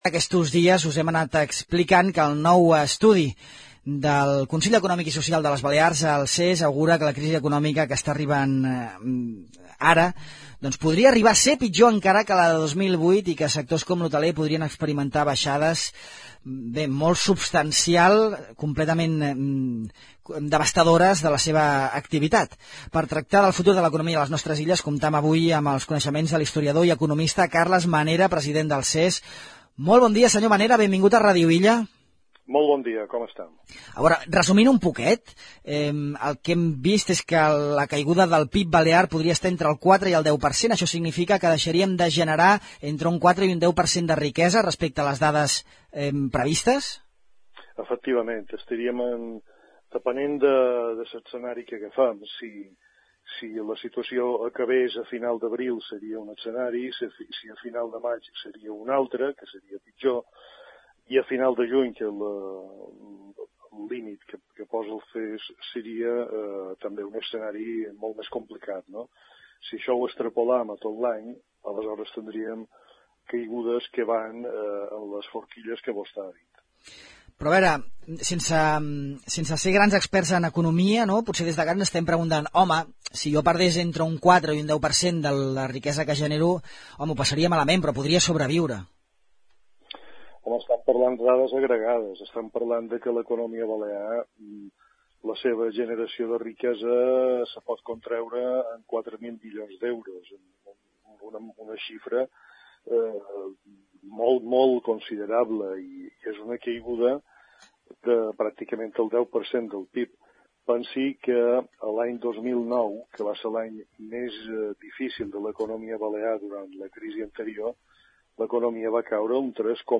La crisi sanitària del coronavirus i les conseqüències de les mesures d’aturada de l’activitat han centrat el darrer estudi del Consell Econòmic i Social de les Balears (CES), realitzat a petició de les patronals, sindicats i altres actors de la societat civil. Tot seguit podeu escoltar l’entrevista mantinguda amb l’historiador i economista Carles Manera, president del CES.